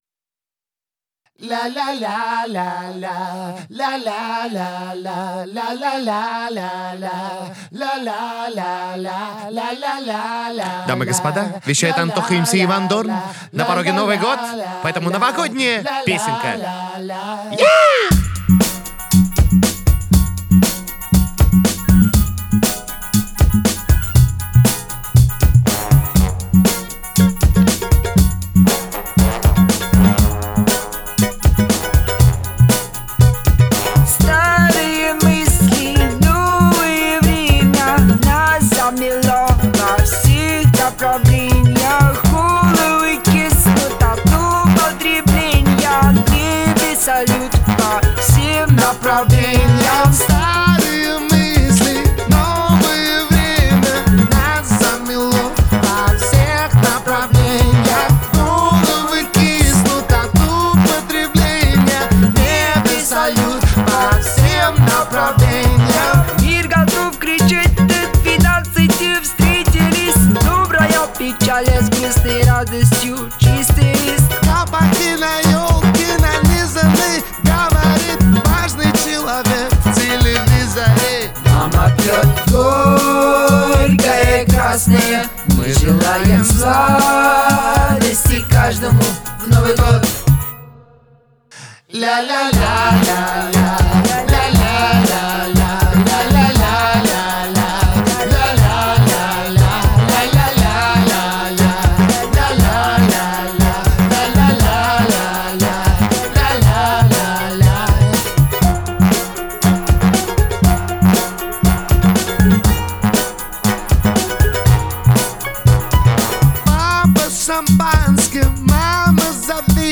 это яркая и энергичная композиция в жанре поп